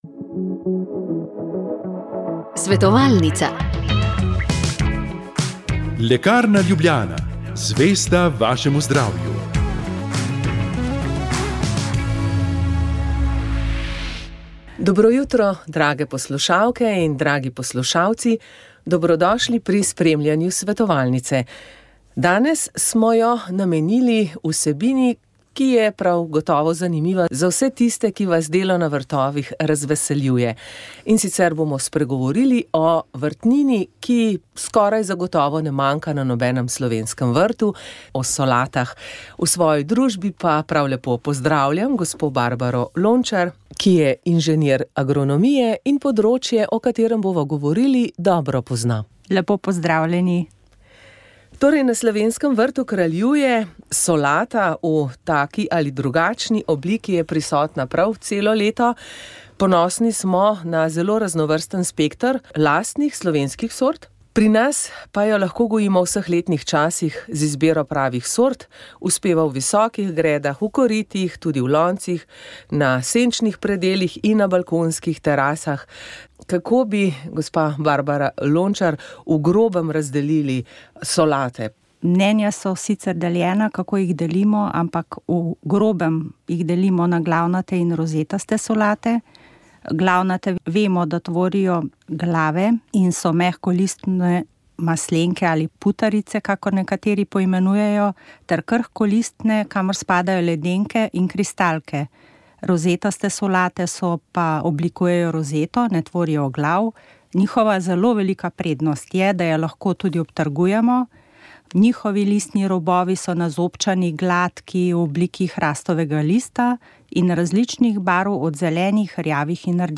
Kmetijska oddaja je predstavila reportažo z rednega občnega zbora Združenja kmečkih sirarjev Slovenije, ki je potekal v nedeljo, 9.februarja 2025, v Mariboru.